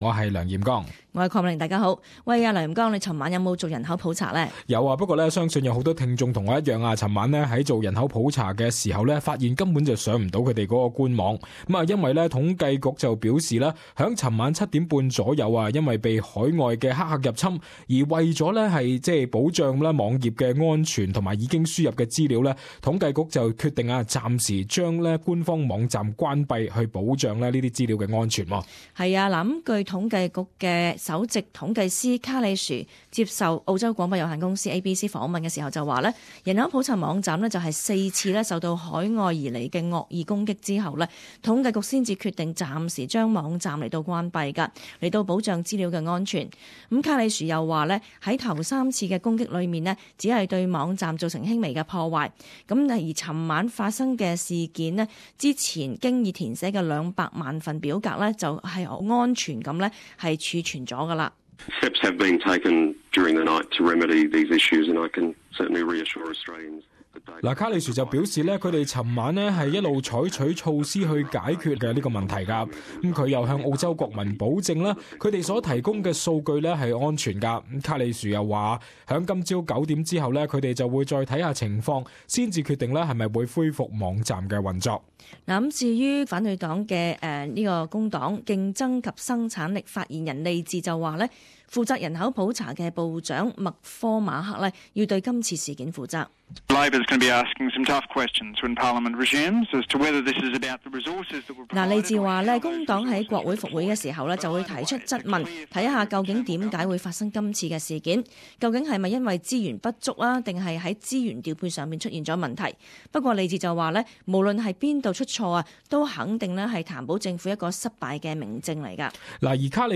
[時事報導]人口普查網站遭網絡攻擊需關閉